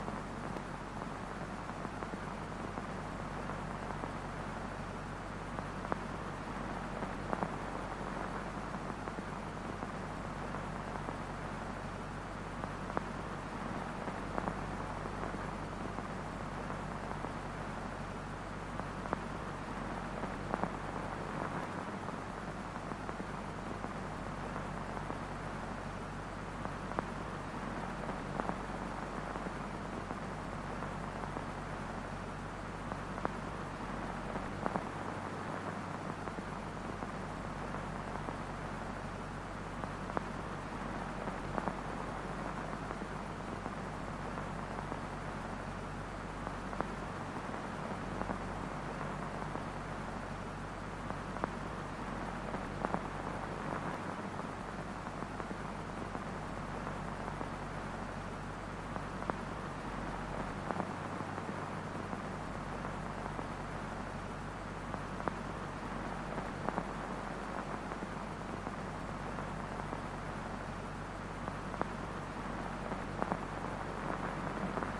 played at 80% speed